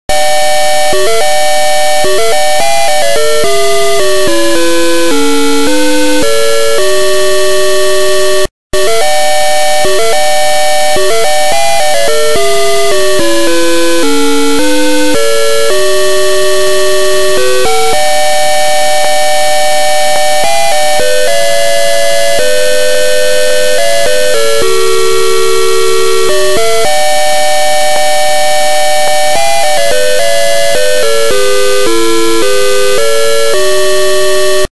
Generování dvouhlasé „hudby“ na jednobitovém výstupu – verze II
Zvukový záznam skladby (formát WMA)
Oproti původnímu přehrávači s osmibitovým rozlišením má tento poněkud „zastřenější“ projev.